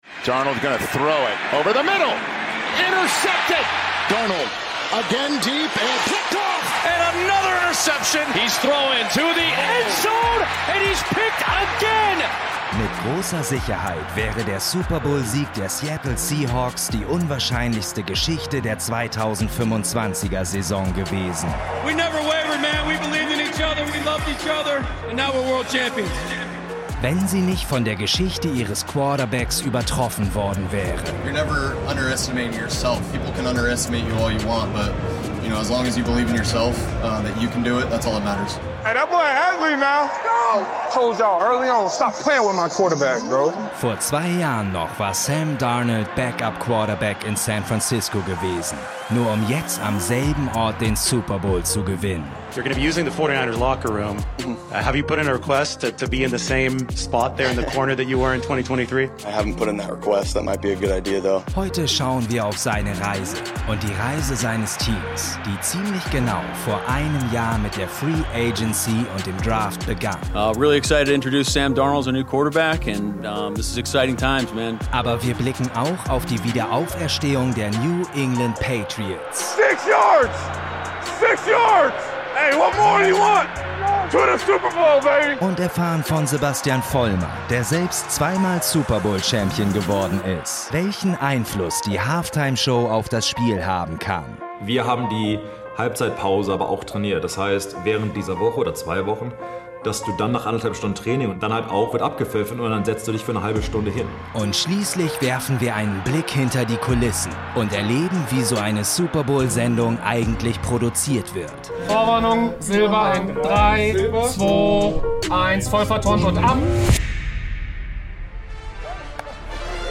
Wir begleiten die Mitarbeitenden bei der Probe, lauschen den Expertinnen und Experten im Backstage und hören, wie bei allen der Stress abfällt, wenn mal zwei Minuten Werbepause ist.